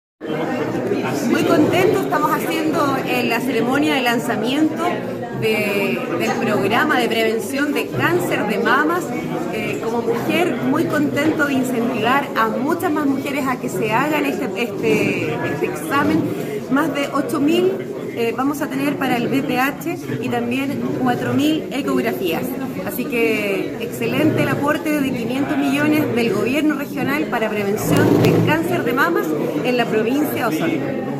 Mientras que la consejera regional, Andrea Iturriaga, informó que gracias a estos recursos se podrán realizar cerca de ocho mil exámenes para detectar el Virus del Papiloma Humano y también más de 4.000 ecografías.